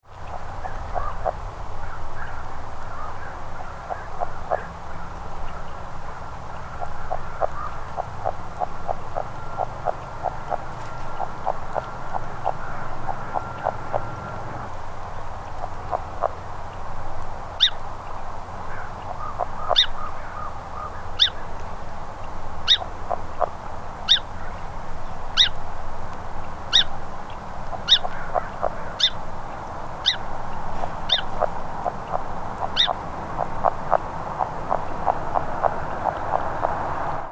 The vocalizations of Rana aurora can be described as a very quiet weak series of 5 - 7 notes, sounding like uh-uh-uh-uh-uh, lasting 1 - 3 seconds.
All sounds were recorded in the air (not underwater) at a close distance with a shotgun microphone.
The calls were very faint, only audible from a few feet away, and infrequent, lasting only for a few seconds between long stretches of silence.
Background sounds include: various birds calling and moving around in dry reeds, including ravens and song sparrows; flowing water; distant traffic; and distant farm equipment.
Sound This is a 37 second recording of the advertisement calls of a single frog. The loud call note of a Song Sparrow is heard in the second half.